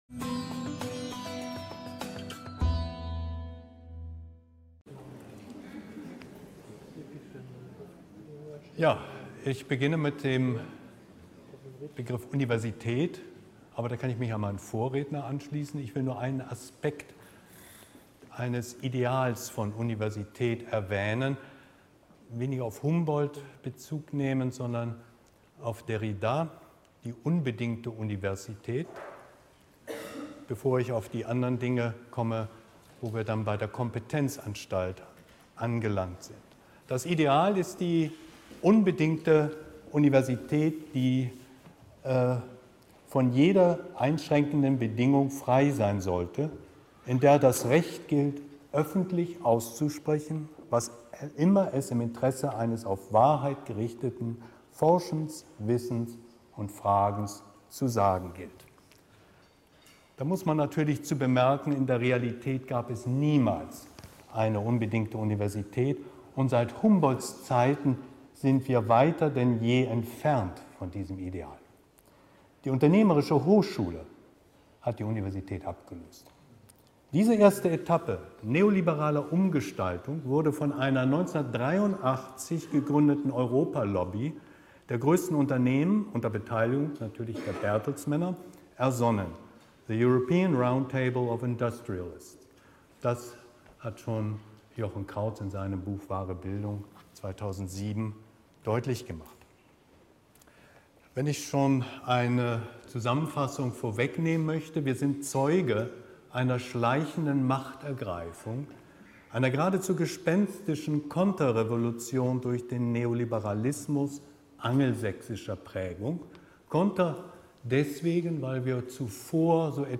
Location: Audimax des Universitätsklinikums Frankfurt